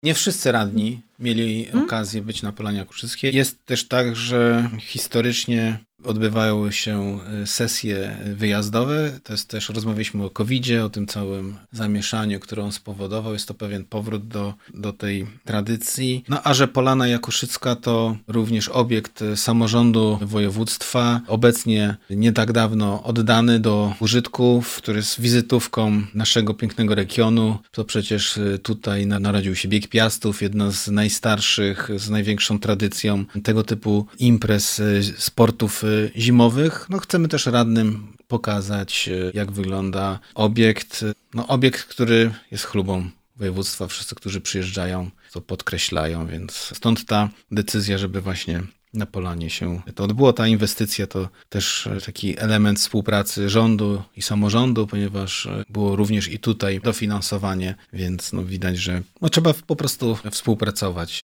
O tym dlaczego sesja odbędzie się w nietypowym miejscu, mówi Marcin Krzyżanowski – Wicemarszałek Województwa Dolnośląskiego.